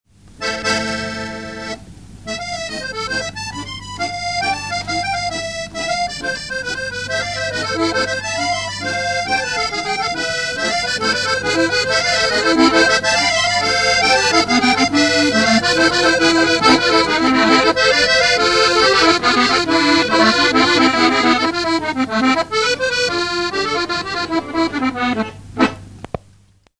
It was much better than our old recorder, but with sound-on-sound recordings the quality got bad quickly.
Here are three of my compositions I recorded with the Uher.
As a study I wrote a short piece and played it three times. The first accordion starts, the second follows, then the  third (a catch).